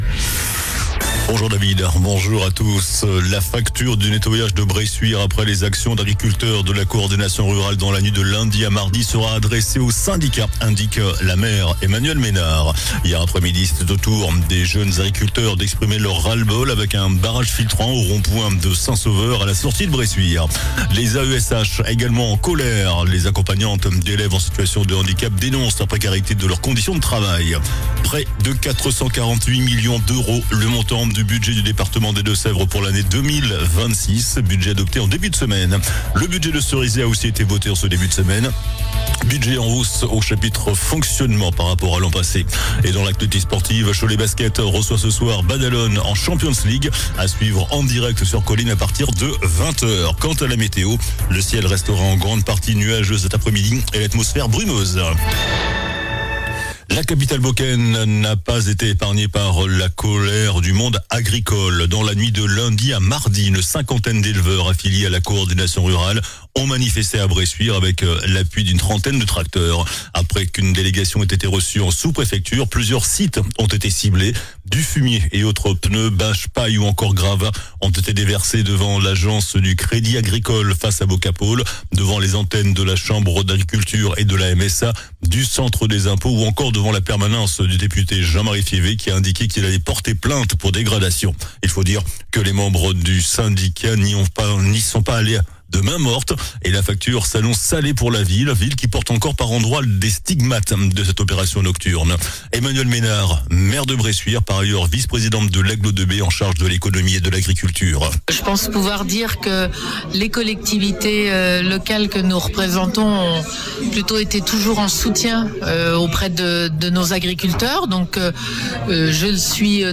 JOURNAL DU MERCREDI 17 DECEMBRE ( MIDI )